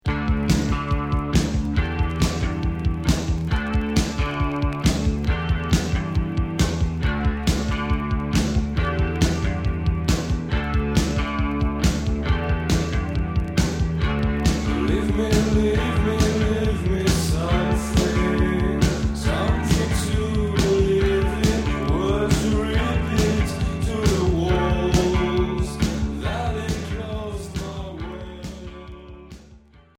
Rock Cold wave